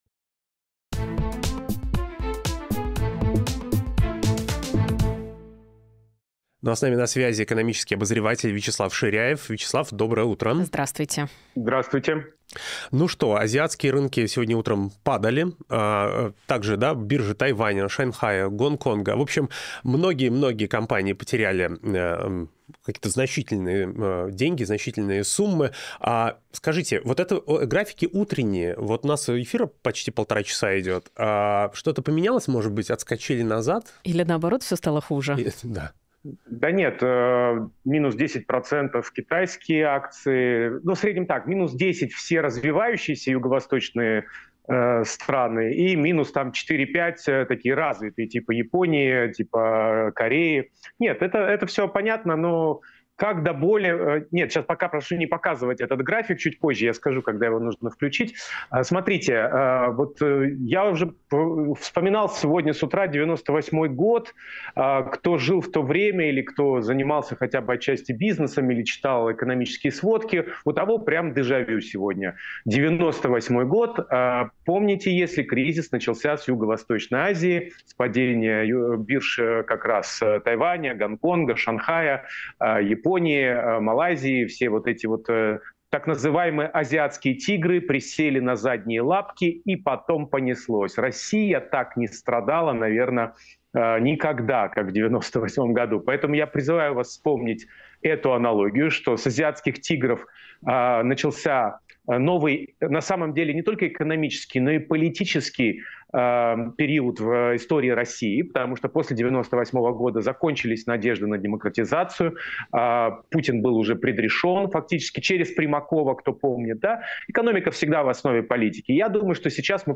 Фрагмент эфира от 07.04